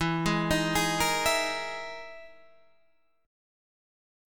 EM7b5 chord